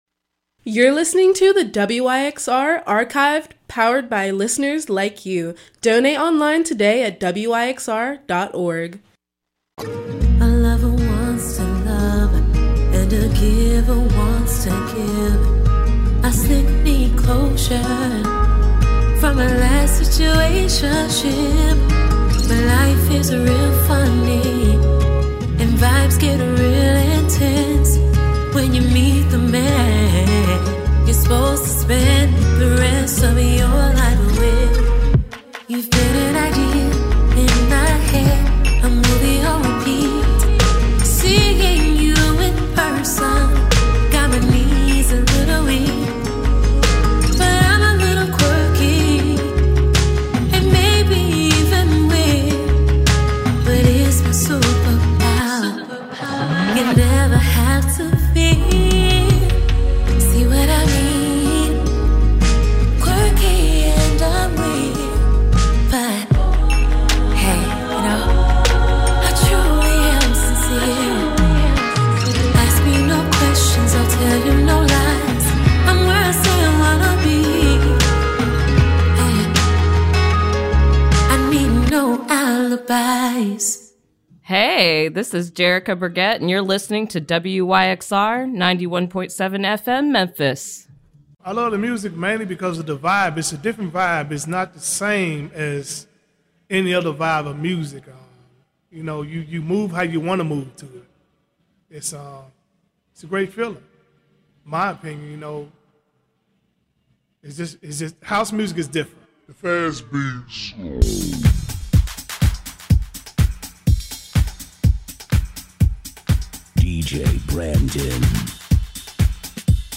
House Deep House